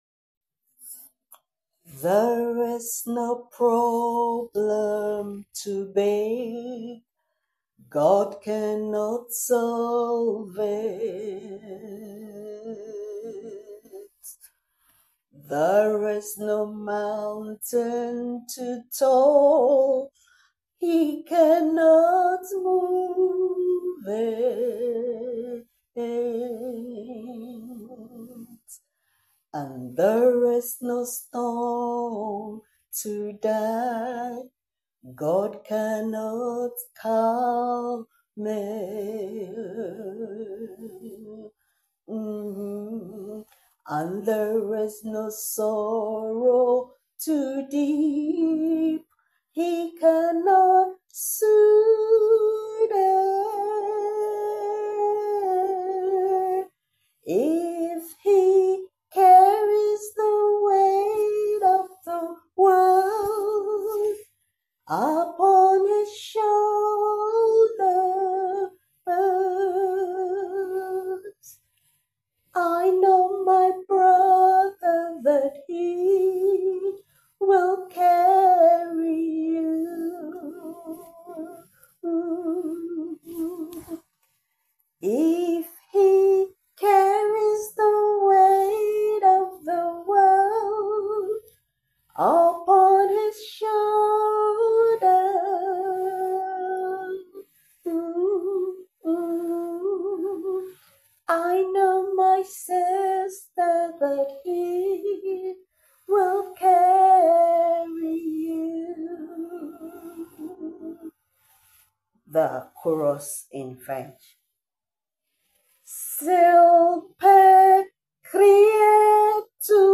Song for meditation